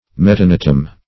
Search Result for " metanotum" : The Collaborative International Dictionary of English v.0.48: Metanotum \Met`a*no"tum\, n. [NL., fr. Gr.